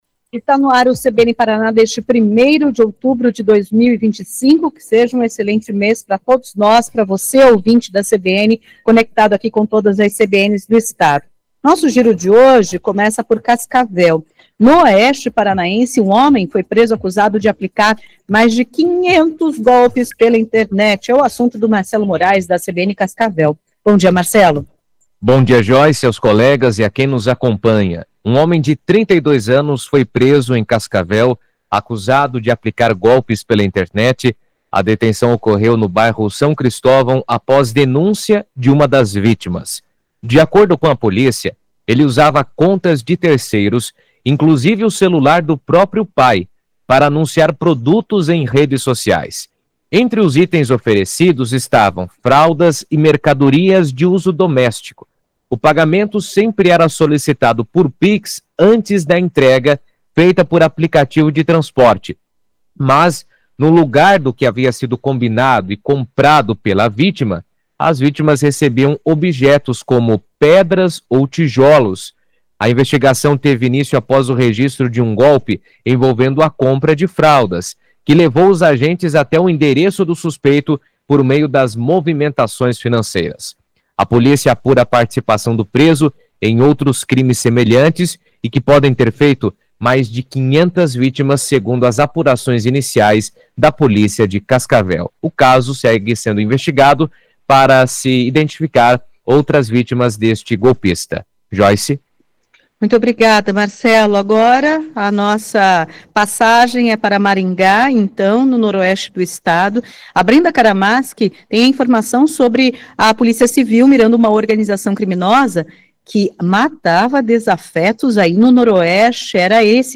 O CBN Paraná vai ao ar de segunda a sexta-feira, a partir das 10h35, com participação da CBN Maringá, CBN Curitiba, CBN Londrina, CBN Cascavel e CBN Ponta Grossa.